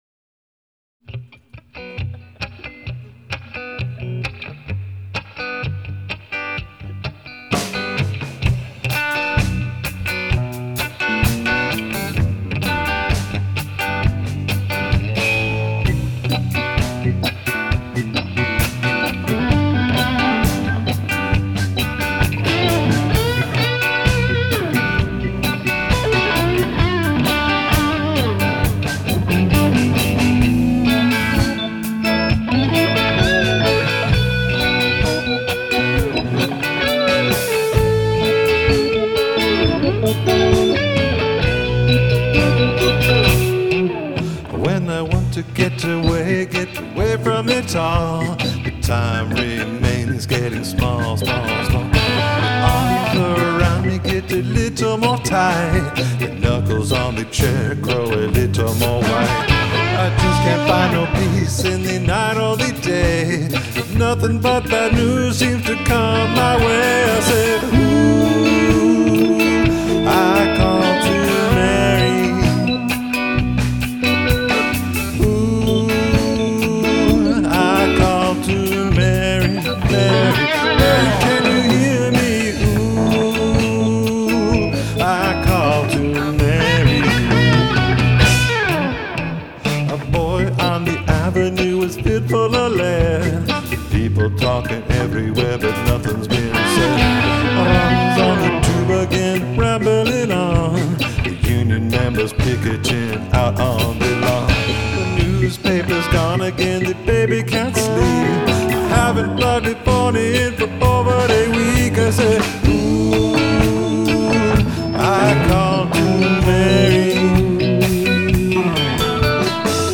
Seattle Peace Concert (Seattle) - 7/11/10